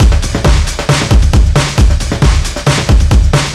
Tough Break 135.wav